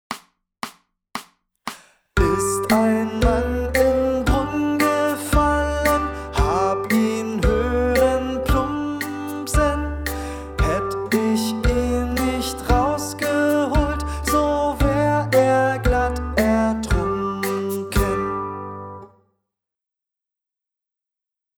mittel